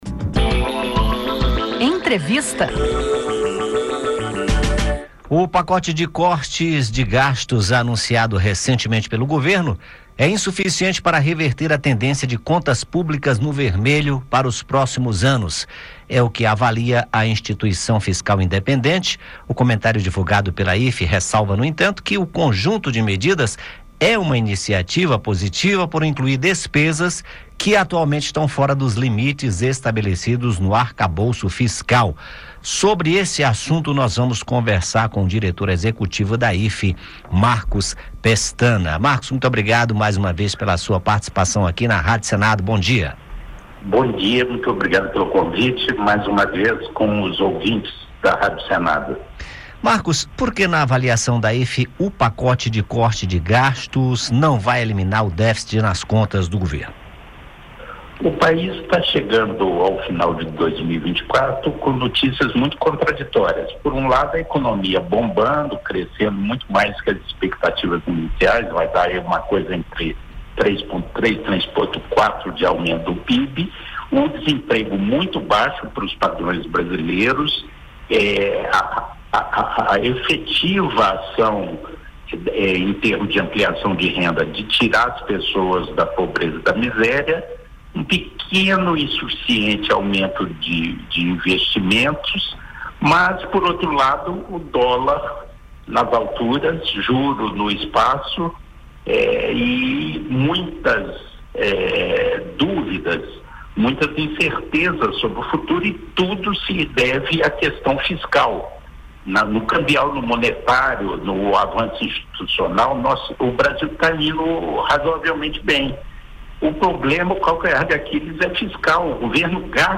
Marcus Pestana, diretor-executivo da Instituição Fiscal Independente (IFI), comenta o pacote de corte de gastos anunciado pelo governo. Para a instituição, as medidas são insuficientes.